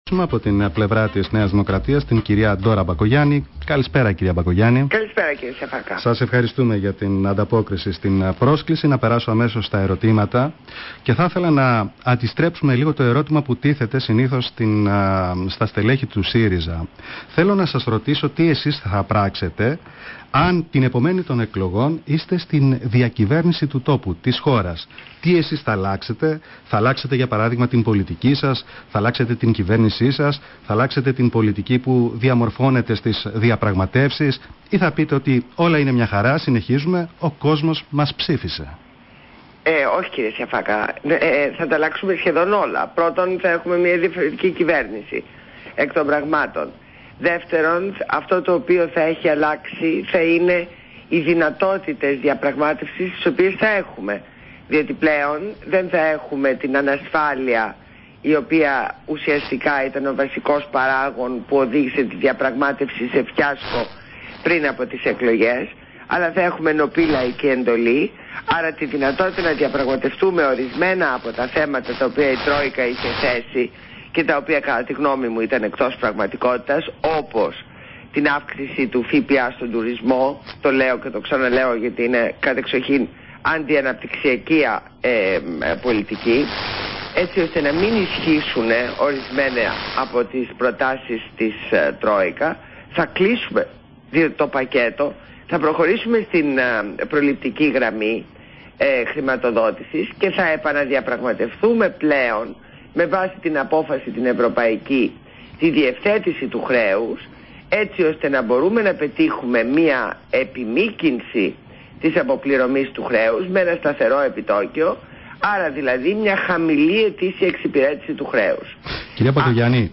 Συνέντευξη Ν. Μπακογιάννη στο ραδιόφωνο της ΝΕΡΙΤ.